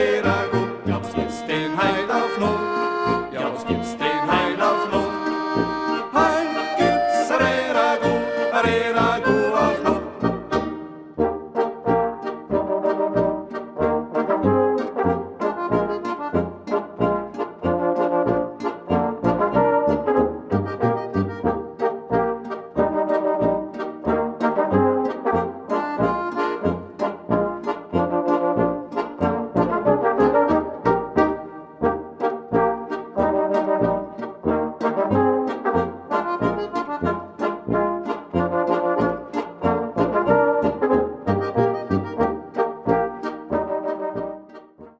Gattung: Volksmusik natürlicher und ursprünglicher Art
Besetzung: Volksmusik/Volkstümlich Weisenbläser